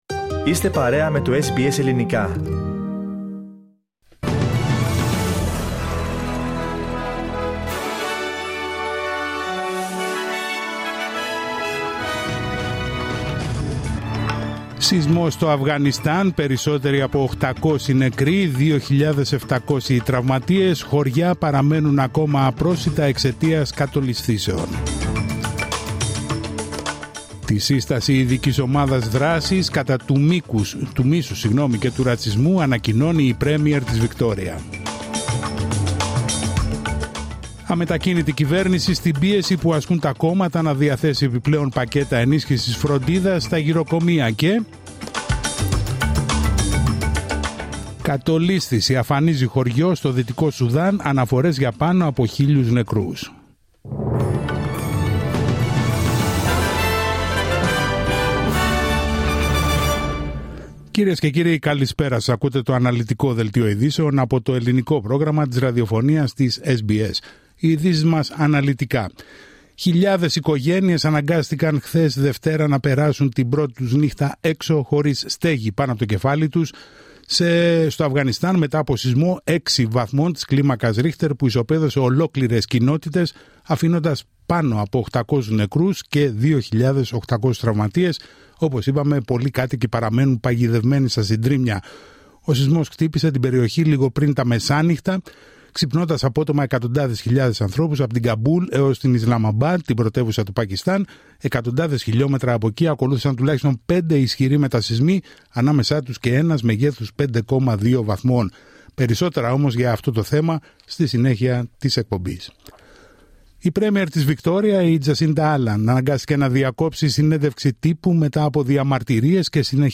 Δελτίο ειδήσεων Τρίτη 2 Σεπτεμβρίου 2025